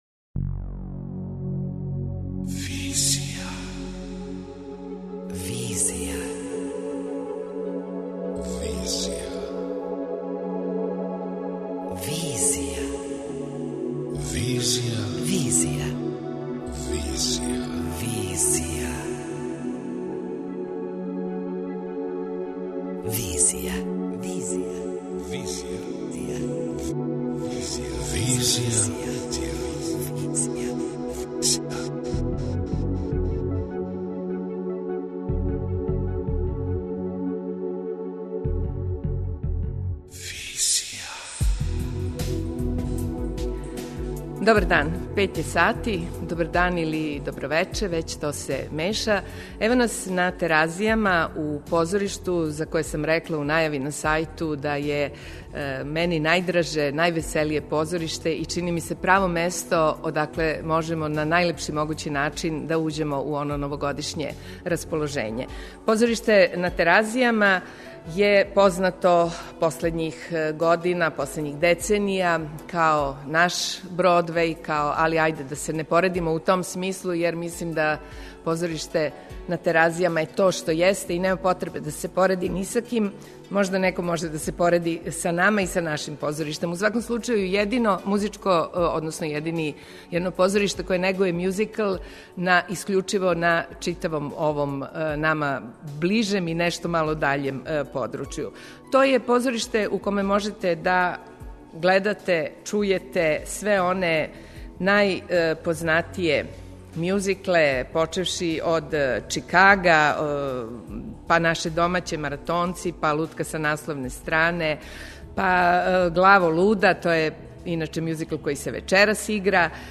Specijalno izdanje Vizije i Ars, Artifexa danas emistujemo iz ovog najglamuroznijeg i najveselijeg domaćeg teatra, kao lagani uvod u novogodišnje raspoloženje, uz najrađe slušane numere muzičkog teatra.